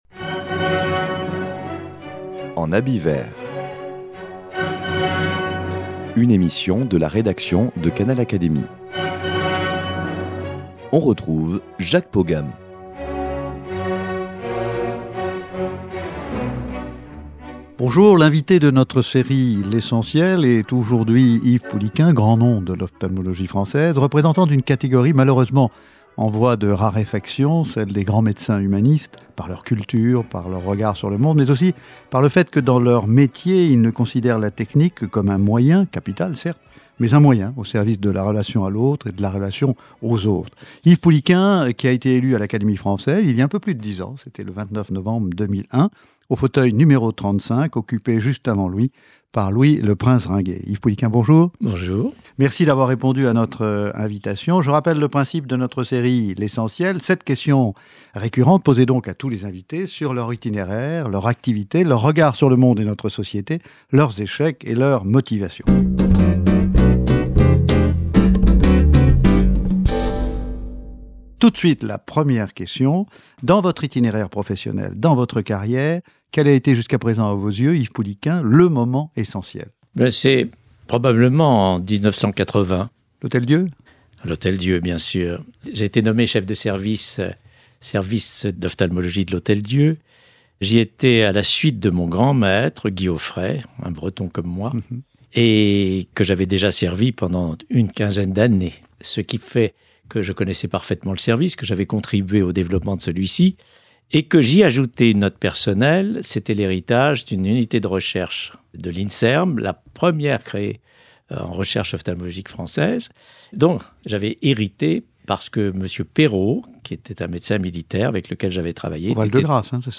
L’invité de notre série l’Essentiel est aujourd’hui Yves Pouliquen, grand nom de l’ophtalmologie française, représentant d’une catégorie malheureusement en voie de raréfaction, celle des grands médecins humanistes par leur culture, par leur regard sur le monde, mais aussi par le fait que dans leur métier, ils ne considèrent la technique que comme un moyen, capital certes, mais un moyen au service de la relation à l’autre et de la relation aux autres.